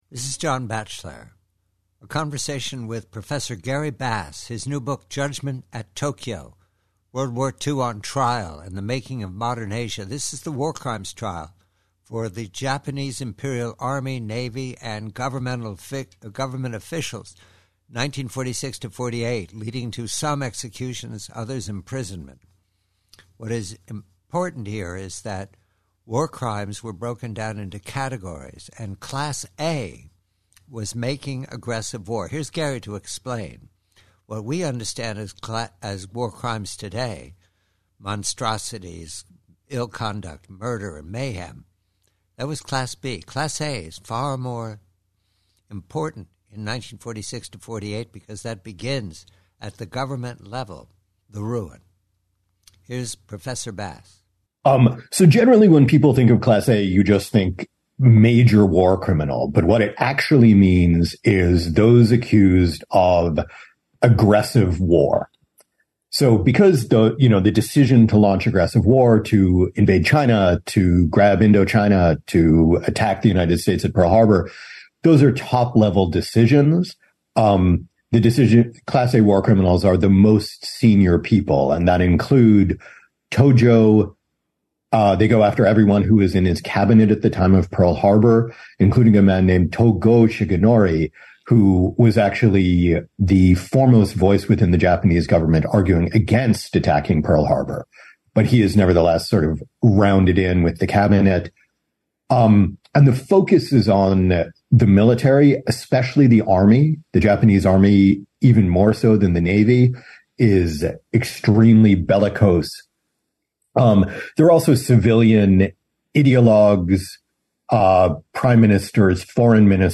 PREVIEW: From a two-hour radio conversation with author Gary Bass re his new book, JUDGMENT AT TOKYO: this excerpt explains the categories of war crimes, especially Class A, making aggressive war, a hanging crime.